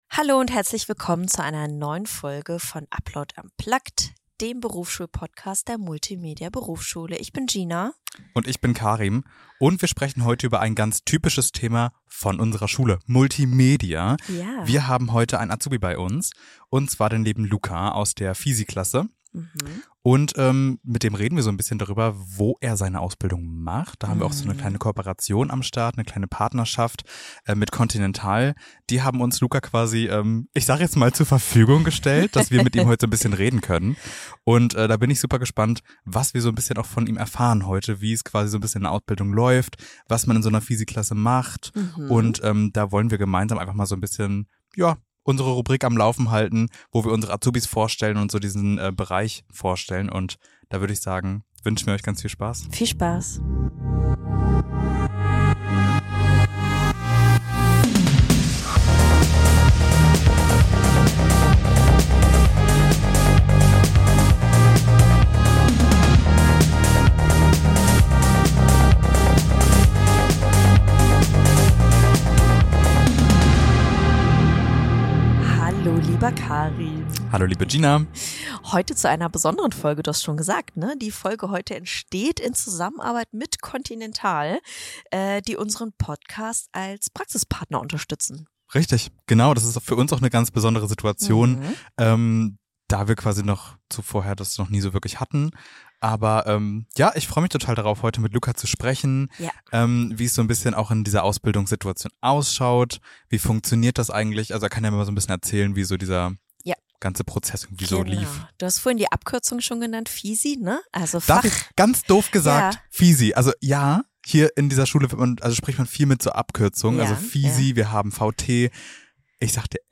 In dieser Folge sprechen wir mit einem Auszubildenden im Bereich Fachinformatik bei Continental über seinen Weg in die IT, den Bewerbungsprozess und den Alltag im Konzern. Wir erfahren, welche Projekte Azubis übernehmen, welche Herausforderungen auftreten und welche Vorteile eine Ausbildung im Großunternehmen bietet. Außerdem sprechen wir über typische IT-Klischees und wie die Realität tatsächlich aussieht.